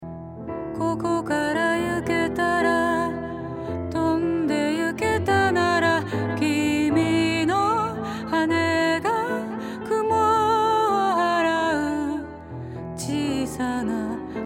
ディレイの重要なパラメーターの一つに「Feedback」があります。
少な目だとこんな感じです。